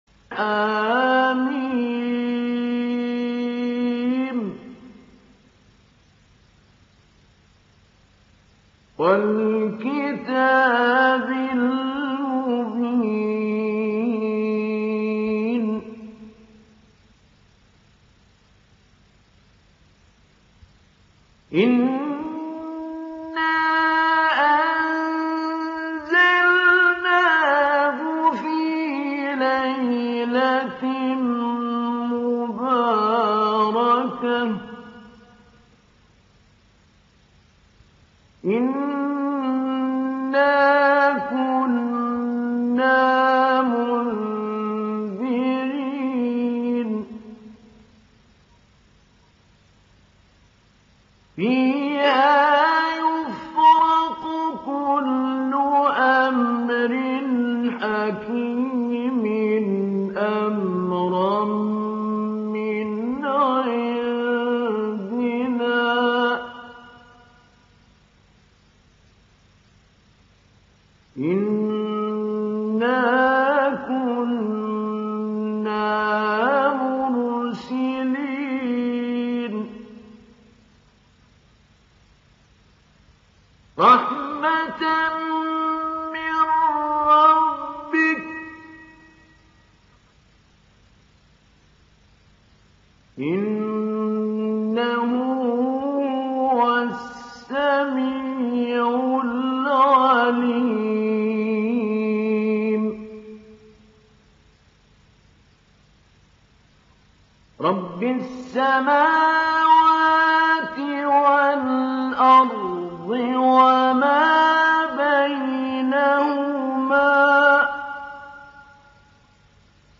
Download Surah Ad Dukhan Mahmoud Ali Albanna Mujawwad